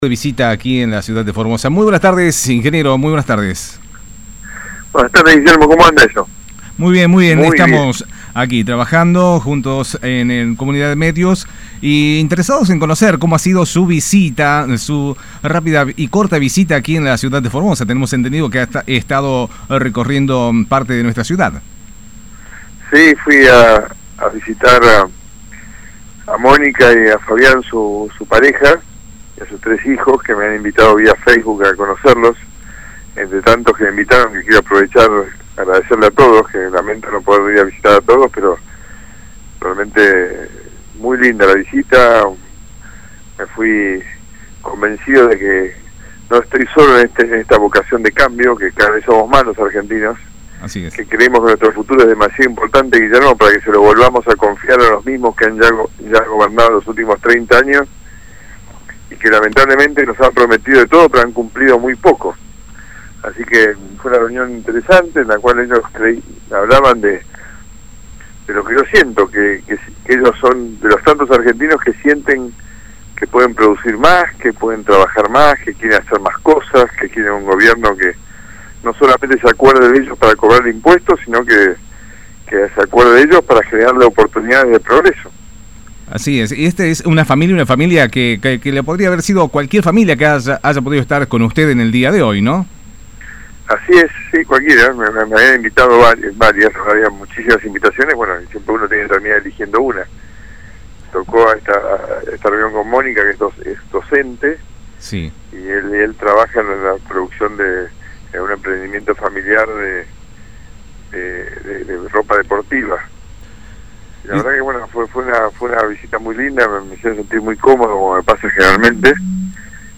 Entrevista_MauricioMacri-VLU-Radio.mp3